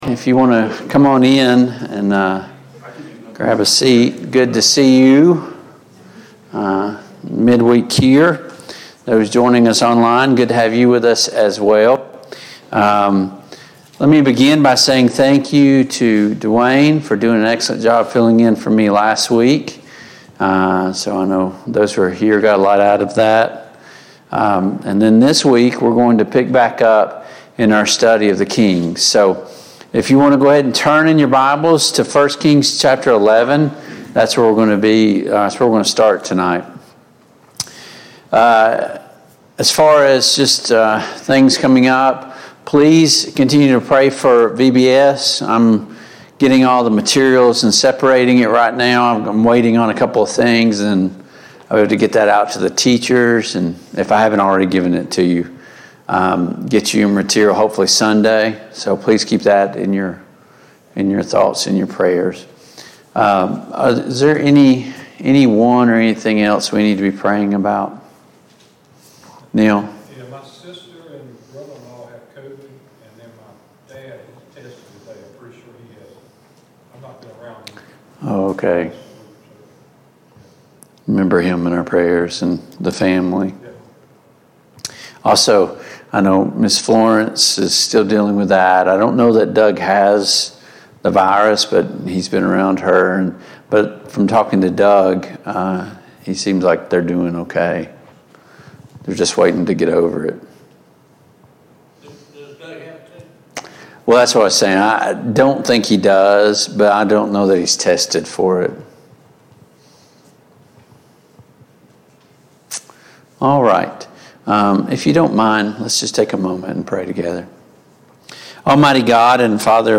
Passage: 1 Kings 11, 1 Kings 12 Service Type: Mid-Week Bible Study